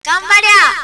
得意の名古屋弁バージョン